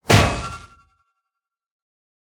smash_air2.ogg